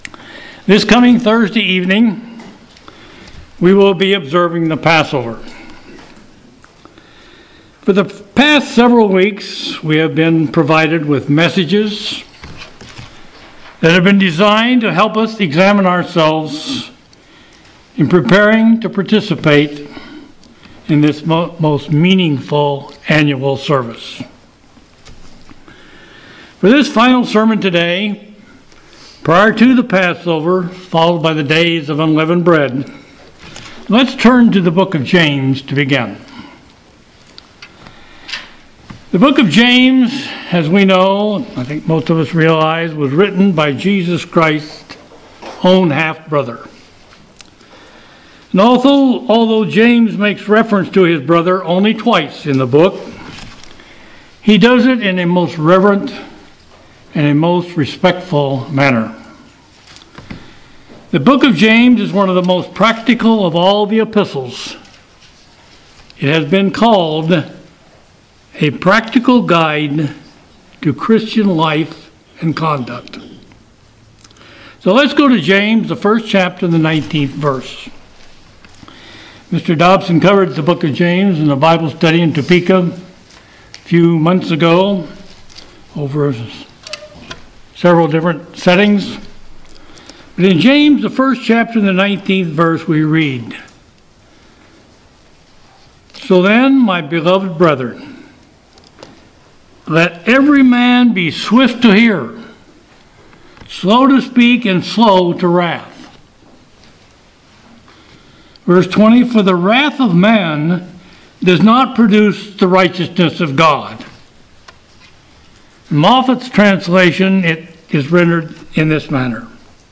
Sermons
Given in Kansas City, KS